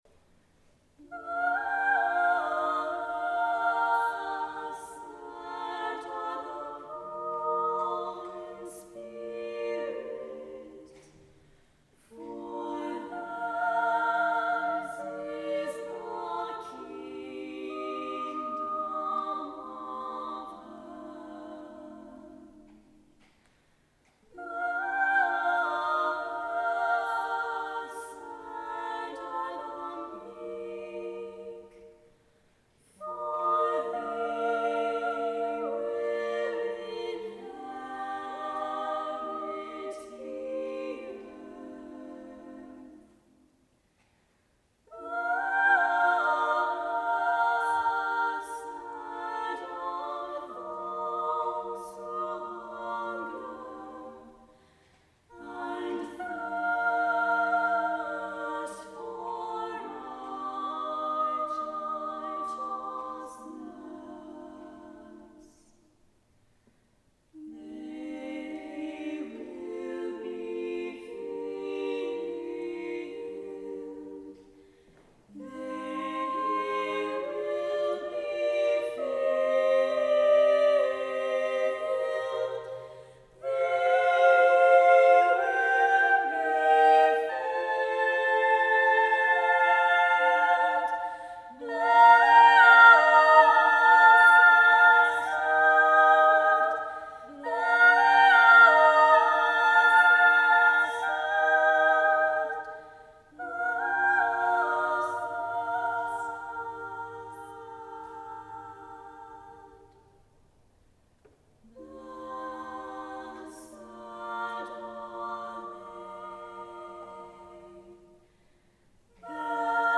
SSAA